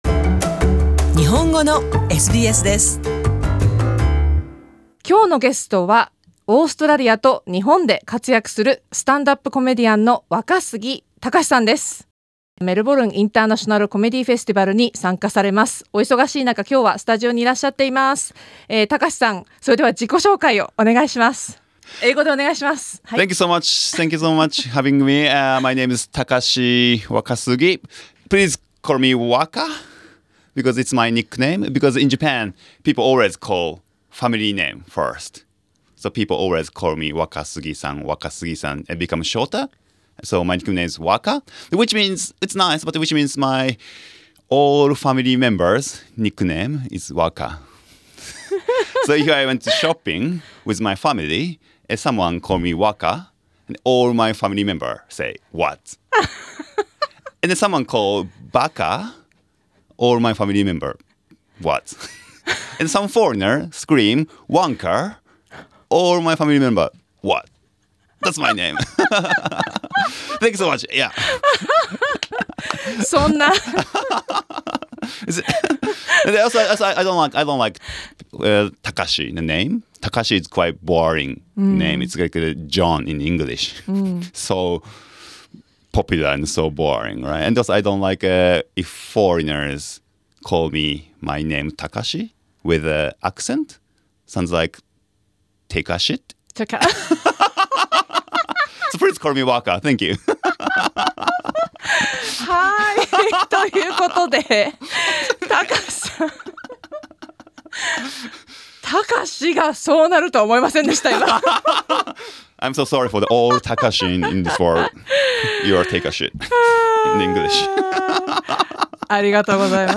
Supplied The interview has been live streamed via facebook.